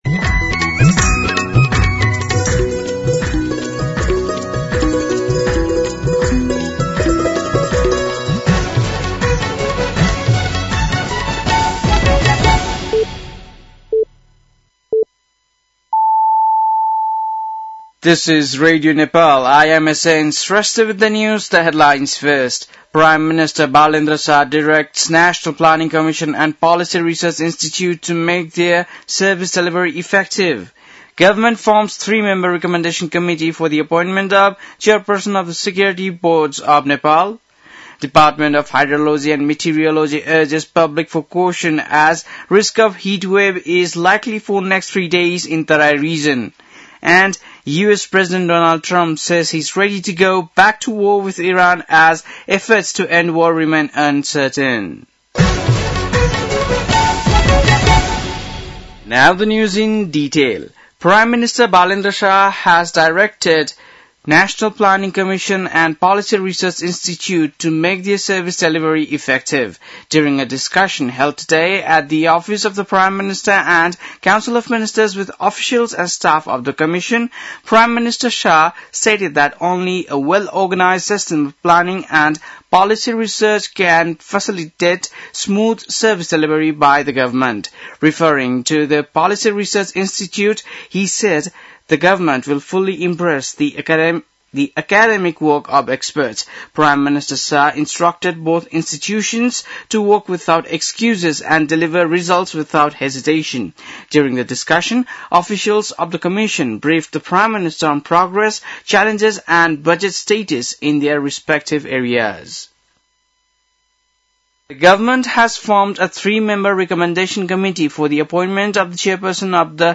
बेलुकी ८ बजेको अङ्ग्रेजी समाचार : ८ वैशाख , २०८३